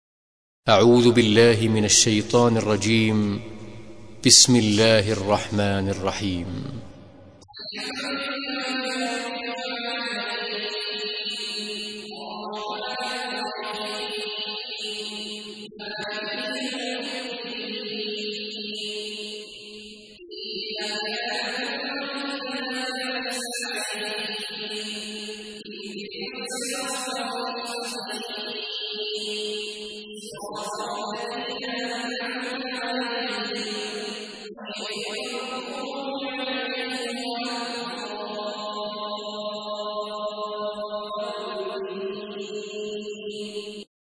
تحميل : 1. سورة الفاتحة / القارئ عبد الله عواد الجهني / القرآن الكريم / موقع يا حسين